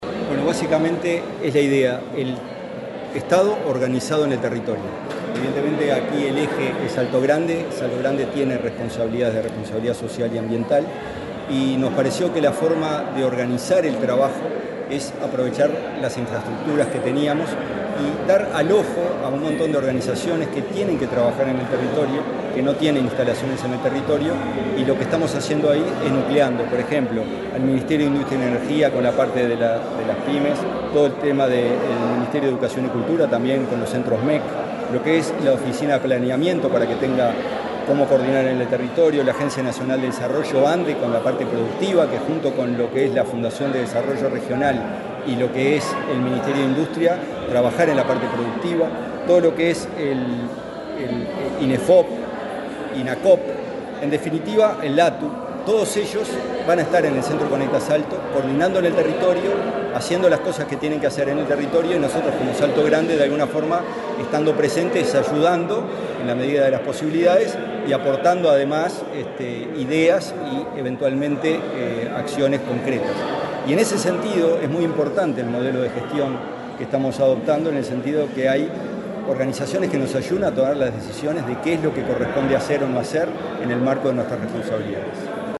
Declaraciones del presidente de la CTM, Gonzalo Casaravilla, sobre Centro Conecta
Declaraciones del presidente de la CTM, Gonzalo Casaravilla, sobre Centro Conecta 24/10/2025 Compartir Facebook X Copiar enlace WhatsApp LinkedIn Tras la inauguración de obras de ampliación del Centro Conecta de Salto, el titular de la delegación de Uruguay en la Comisión Técnica Mixta de Salto Grande (CTM), Gonzalo Casaravilla, brindó declaraciones. Se refirió a la importancia de la presencia del Estado en el territorio.